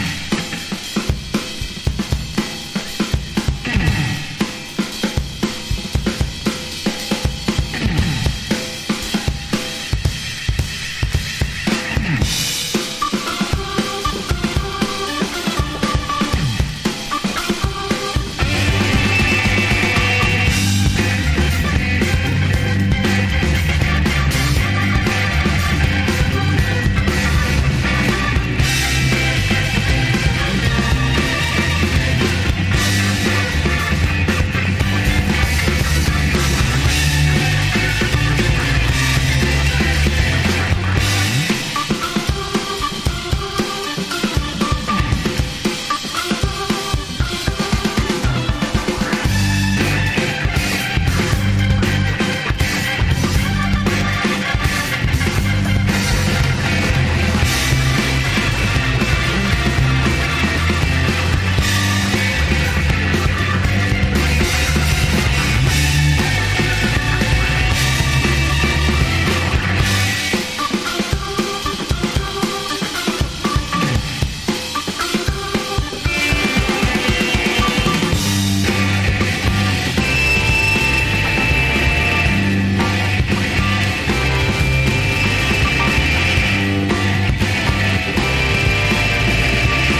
ワシントンDCのガレージ・ブルース～R&Bユニット